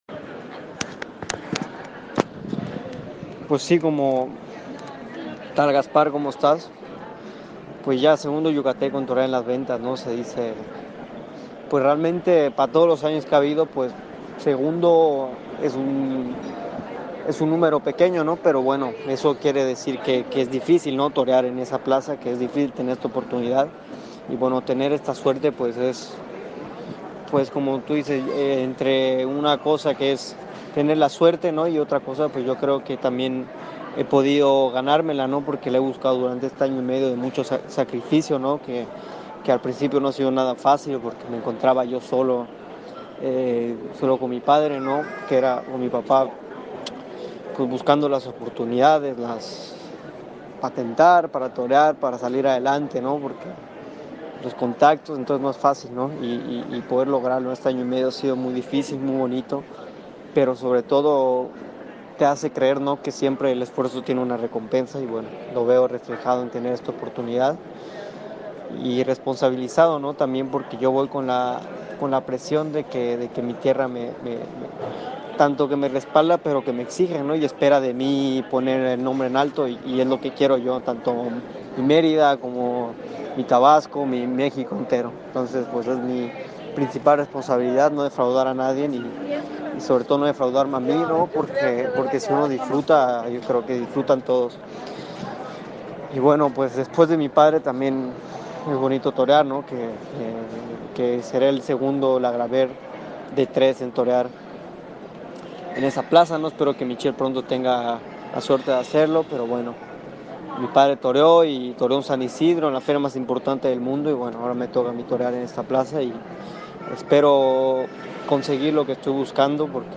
A continuación, el audio de la entrevista: